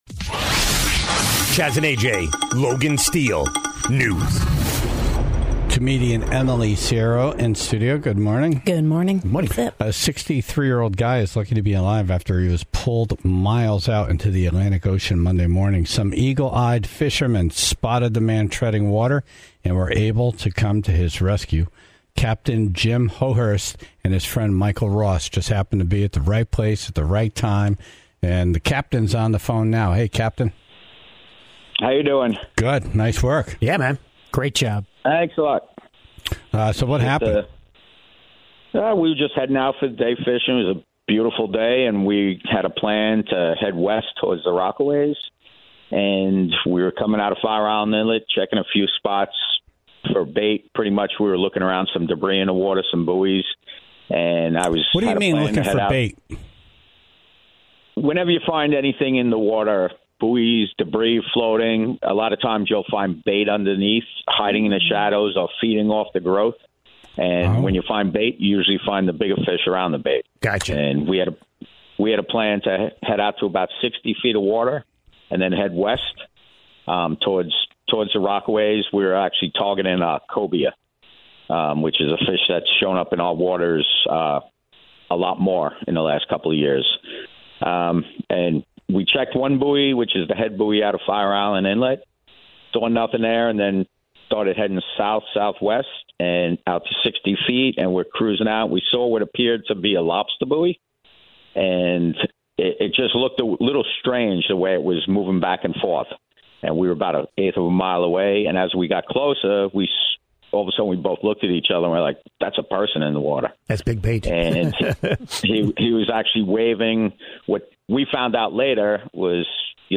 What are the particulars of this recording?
on the phone to talk about the rescue of a man who had drifted out to sea with his fishing pole, and had no way of getting back to shore on his own.